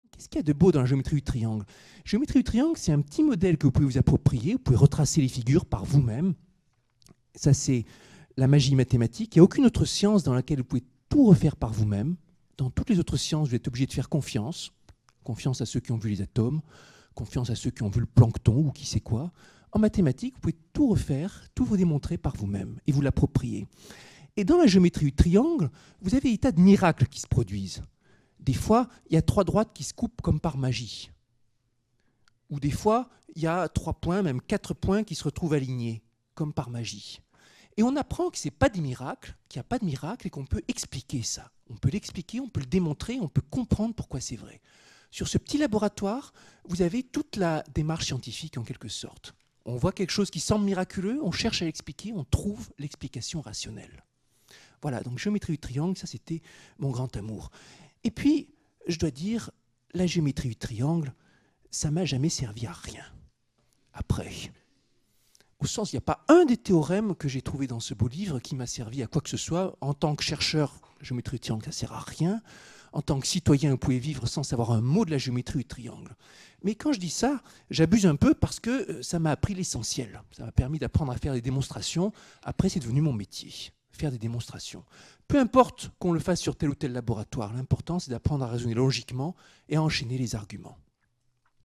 Dans le cadre du séminaire de réflexion sur la pédagogie universitaire, cette table ronde